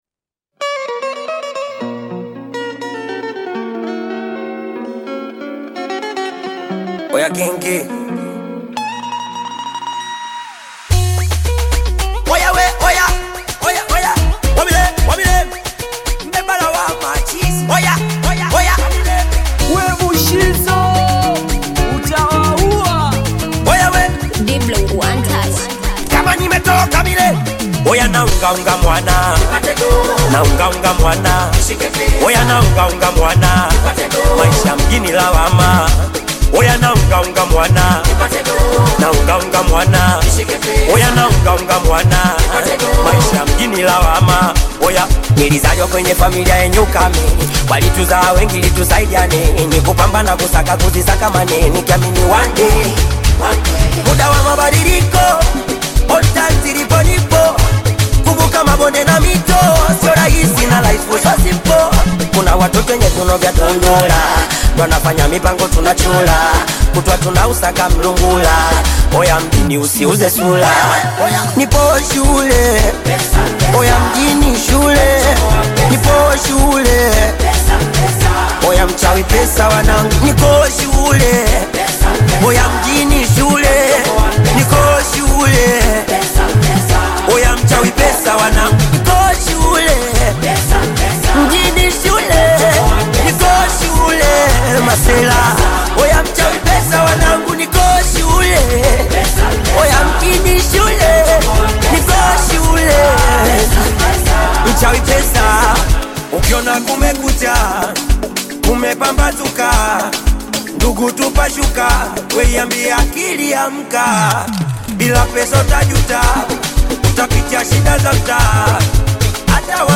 heartfelt track
emotional delivery